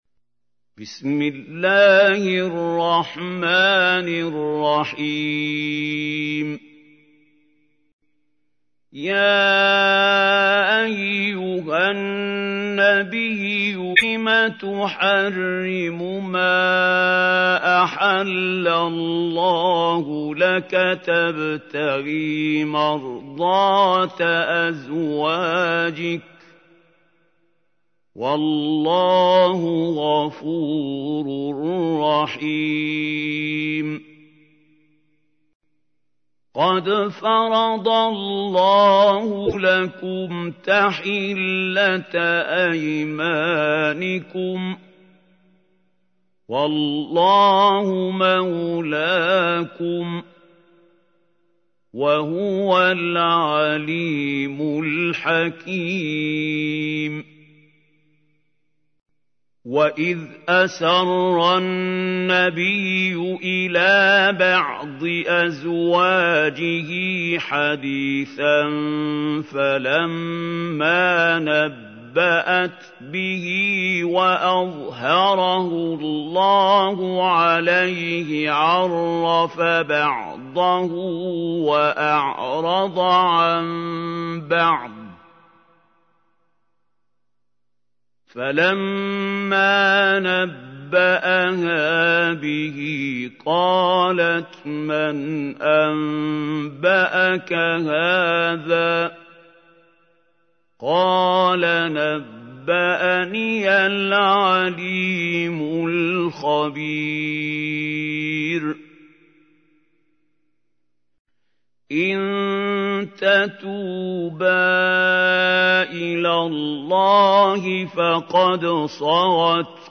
تحميل : 66. سورة التحريم / القارئ محمود خليل الحصري / القرآن الكريم / موقع يا حسين